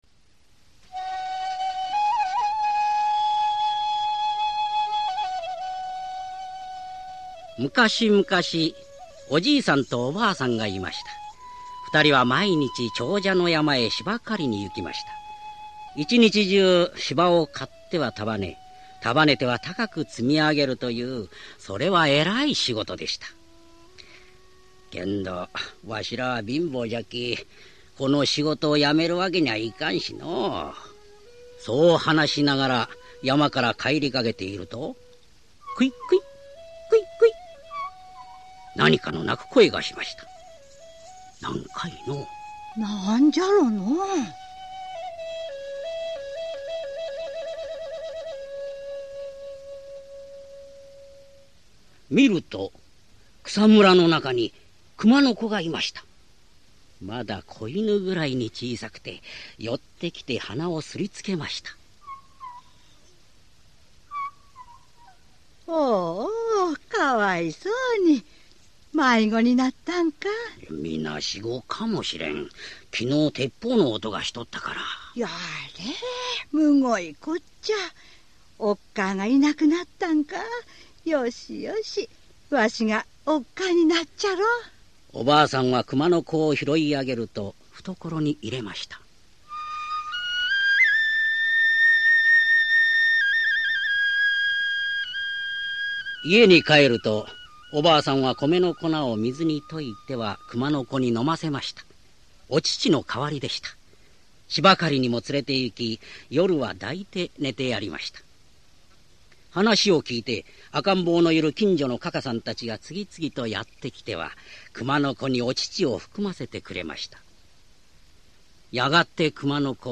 [オーディオブック] くまのおかえし